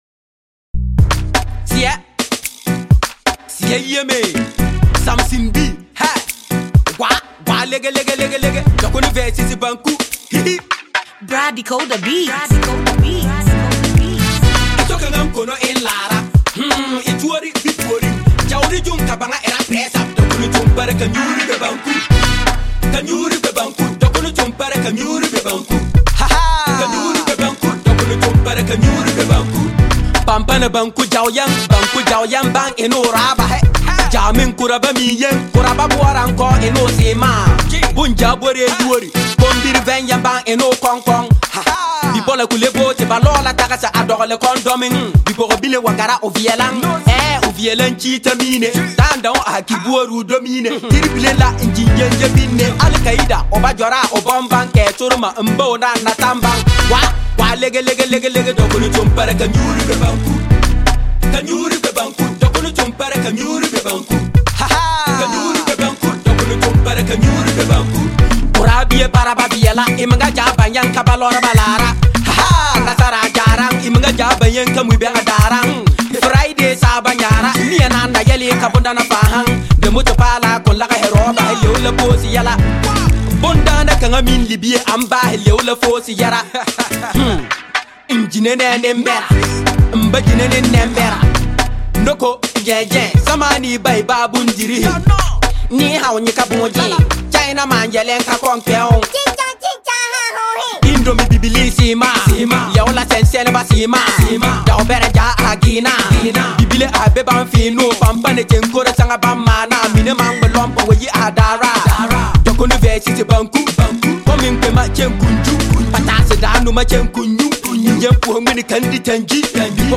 A Comic song